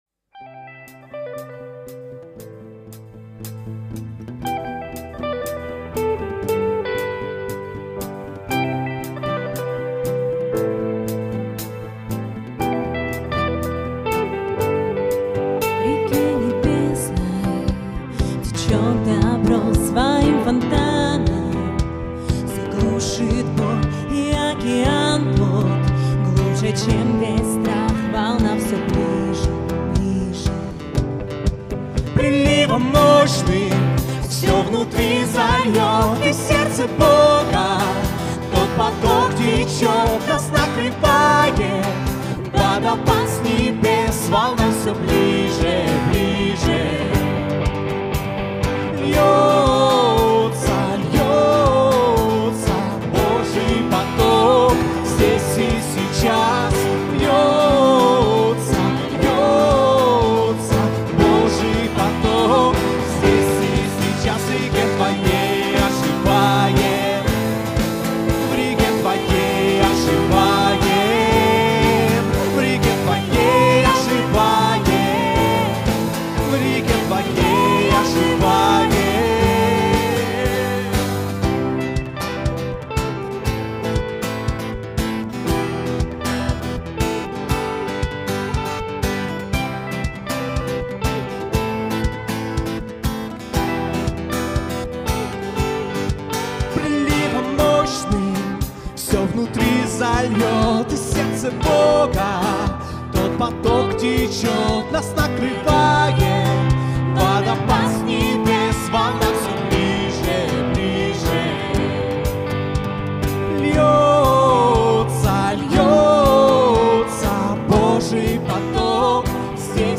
18801 просмотр 1622 прослушивания 133 скачивания BPM: 118